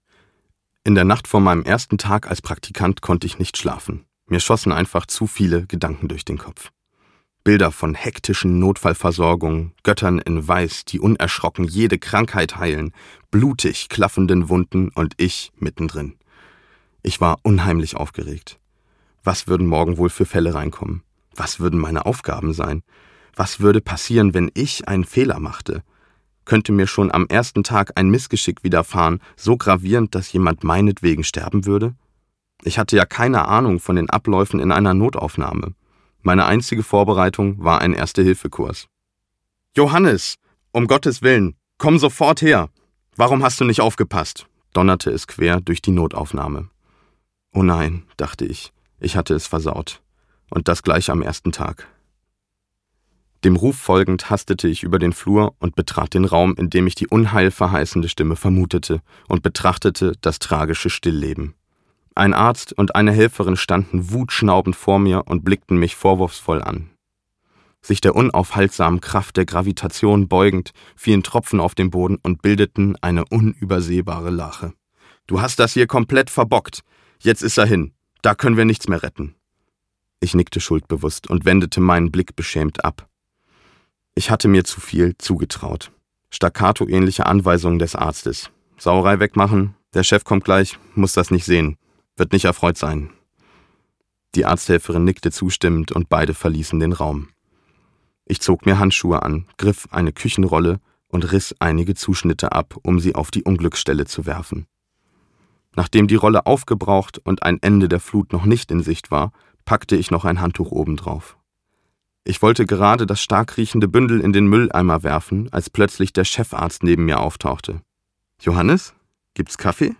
Herzrasen kann man nicht mähen - Johannes Hinrich von Borstel - Hörbuch